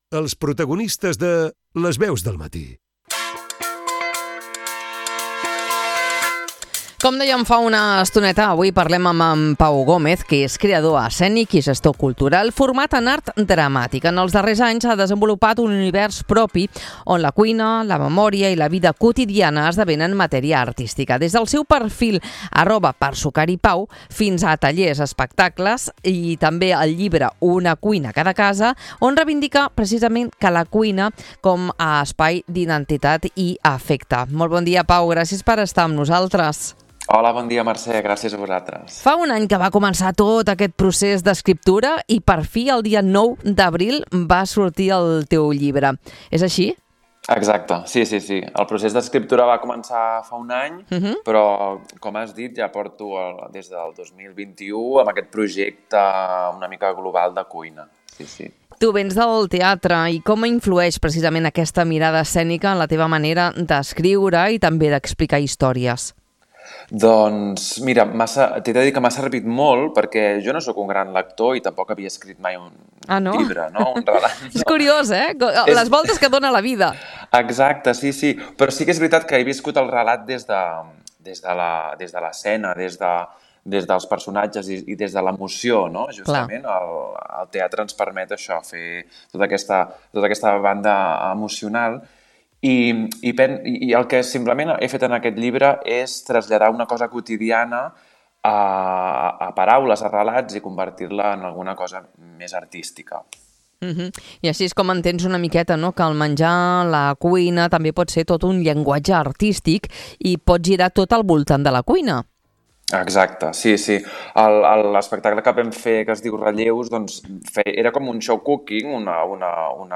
Una conversa que ens convida a mirar la cuina amb uns altres ulls: com un espai de memòria, identitat i afecte. https